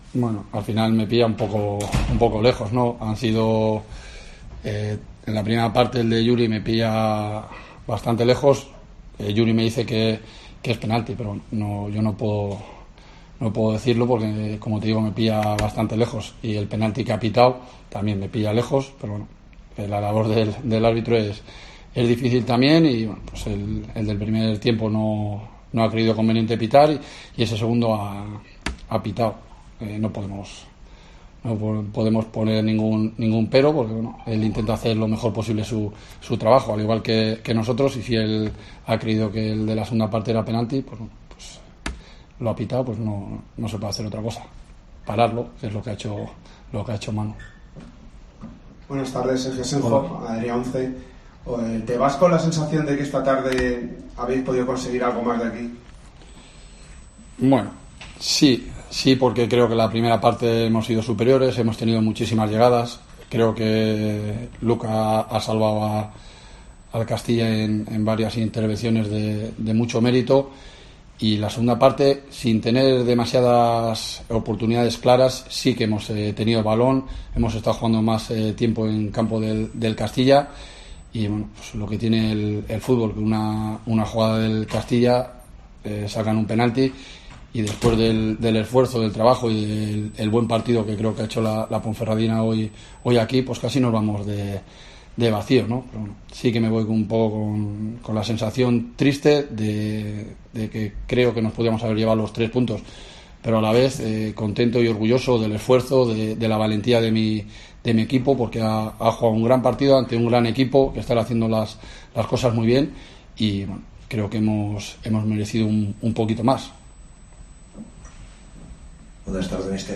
Escucha aquí las palabras del míster de la Deportiva Ponferradina, Jon Pérez Bolo, tras el empate en el campo del Real Madrid Castilla